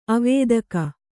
♪ avēdaka